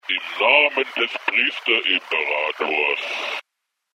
Auf diese Weise fällt das Hintergrundrauschen nicht zu sehr auf.
Da es sich um einen paranidischen Text handelt, werden wir im Script also den Paraniden auswählen.
Nach dem Abschluß des Scriptes hört sich unser kleiner Text so an:
Paranidentest2.mp3